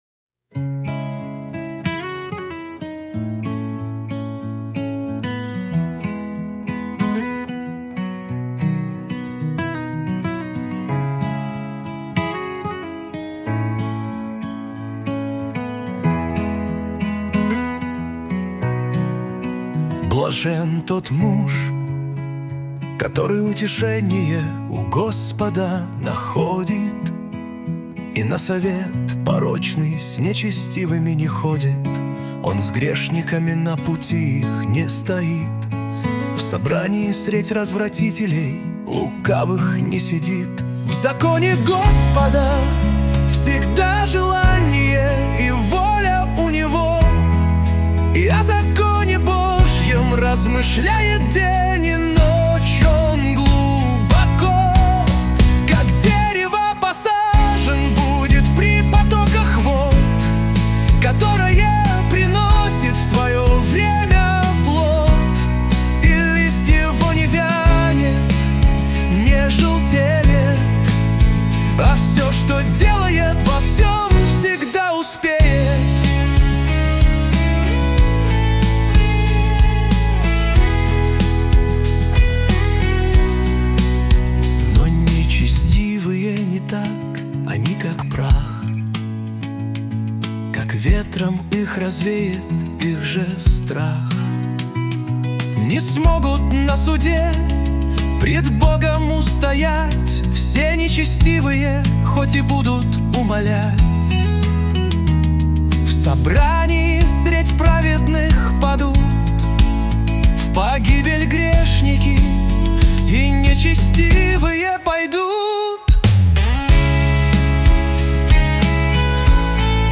песня ai
292 просмотра 1522 прослушивания 100 скачиваний BPM: 93